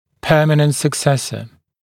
[‘pɜːmənənt sək’sesə][‘пё:мэнэнт сэк’сэсэ]постоянный зуб, который прорезывается на месте временного